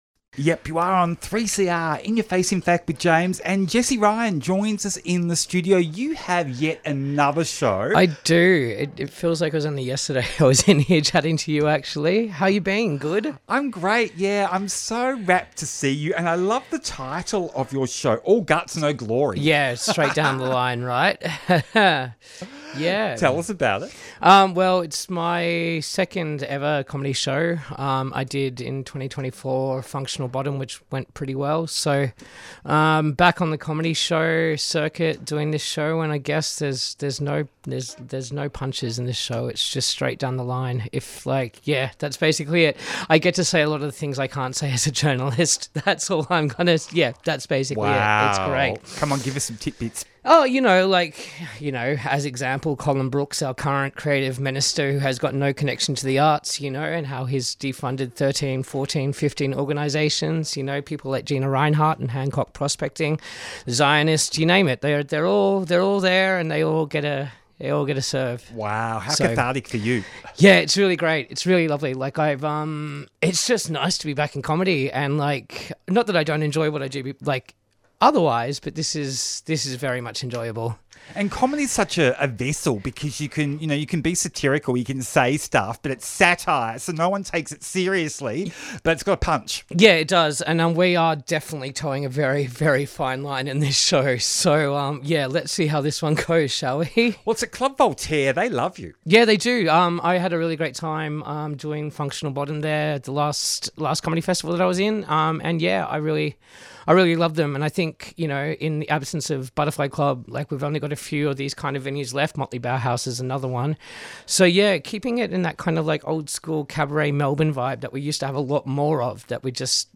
Pride in Protest | Facebook Tweet In Ya Face Friday 4:00pm to 5:00pm Explores LGBTIQA+ issues with interviews, music and commentary.